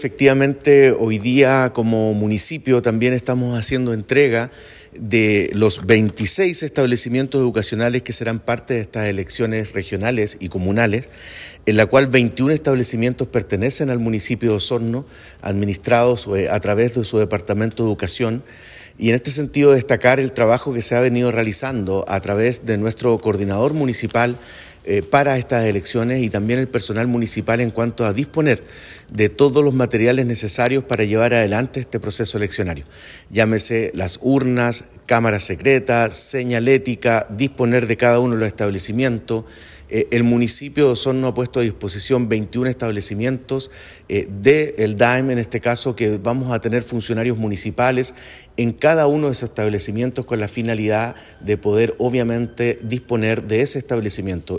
En la comuna de Osorno, son 26 los establecimientos designados como locales de votación, de los cuales 21 son de administración municipal, ante esto el Alcalde (S) Claudio Villanueva indicó que se han entregado todas las facilidades al Ejército para el resguardo de estos espacios, además del trabajo que realizaran funcionarios municipales.